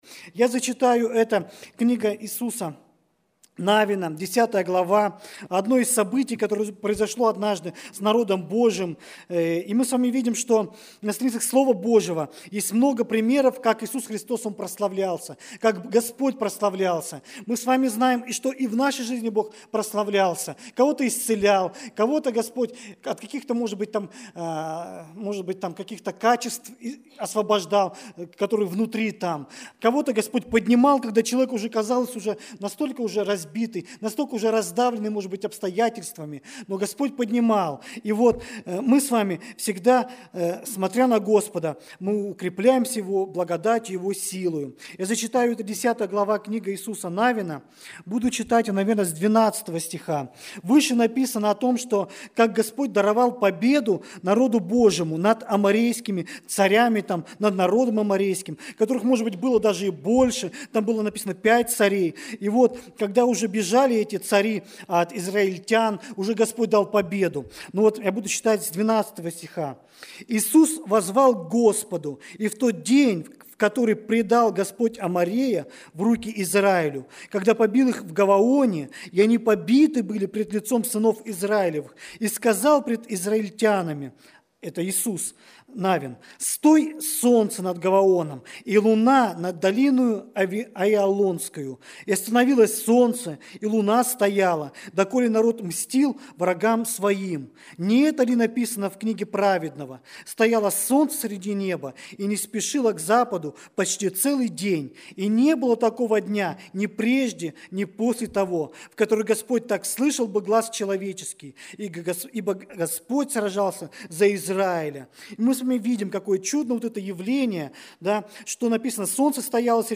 Богослужение 04.01.2023
Проповедь